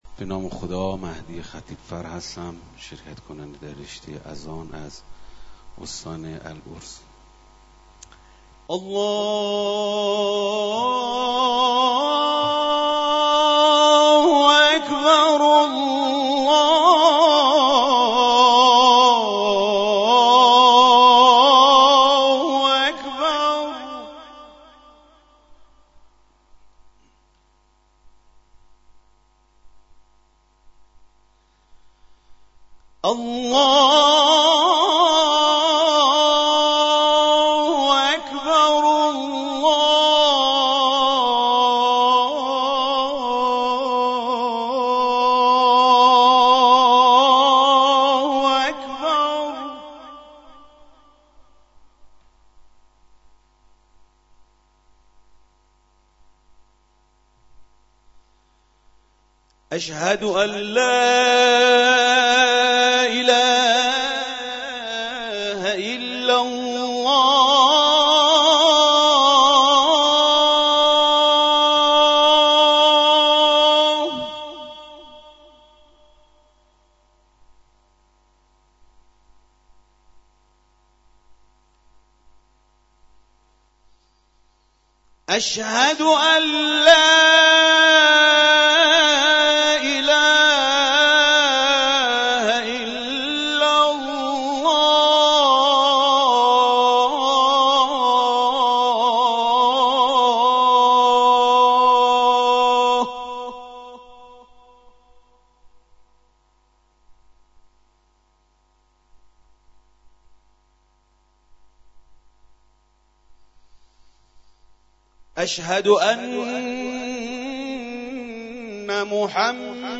برترین مؤذن کشور انتخاب شد+صوت اذان
صراط: با پایان روز چهارم مسابقات سراسری قرآن کریم برترین مؤذنان کشور معرفی شدند.